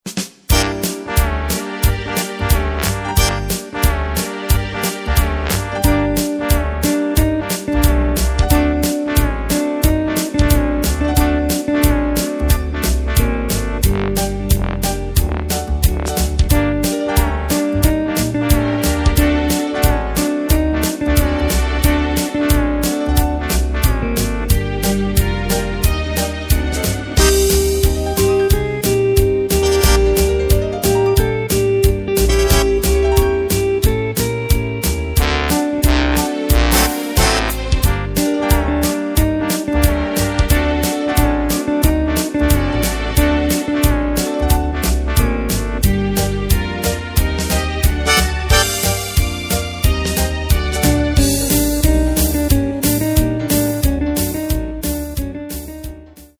Takt:          4/4
Tempo:         90.00
Tonart:            Bb
BigBand Sound aus dem Jahr 2013!